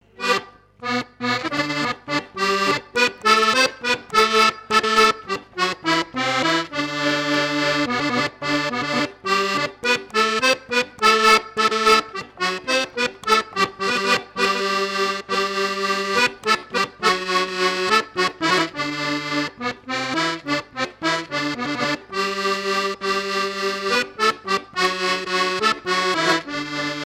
danse : valse
Fête de l'accordéon
Pièce musicale inédite